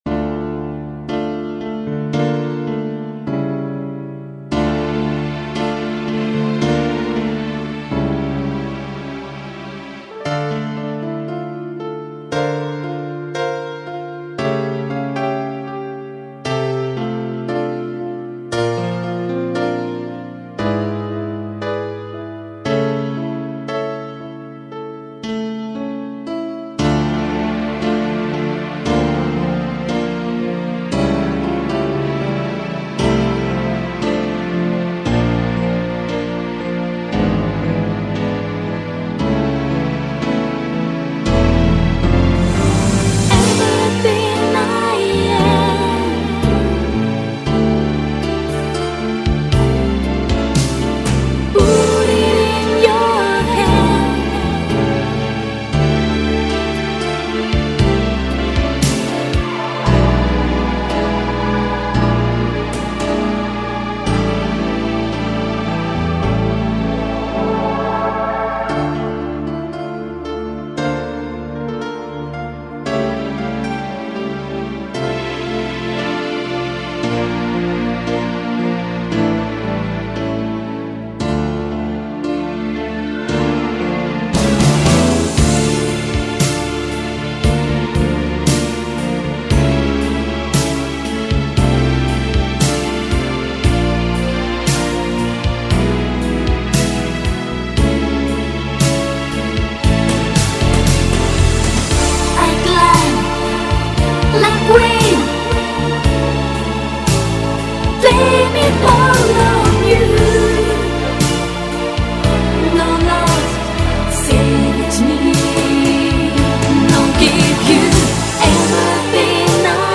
Качество:Ориг+бэк